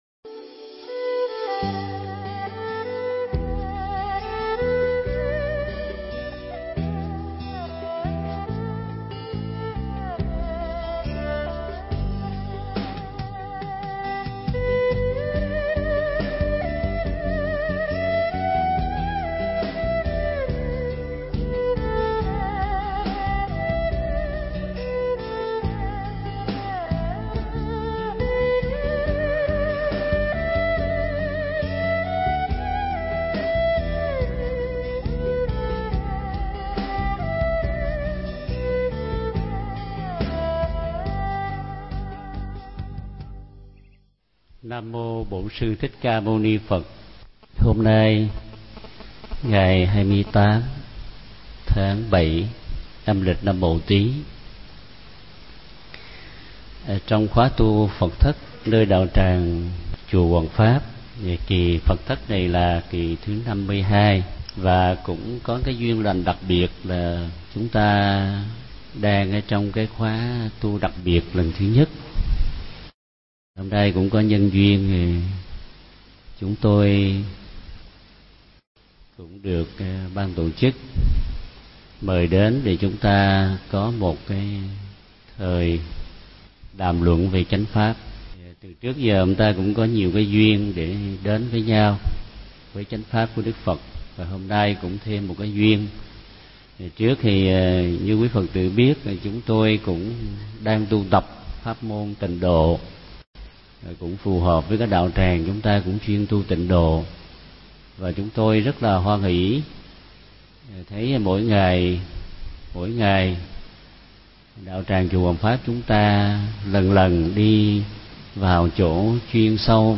Nghe Mp3 thuyết pháp Tùy Duyên Niệm Phật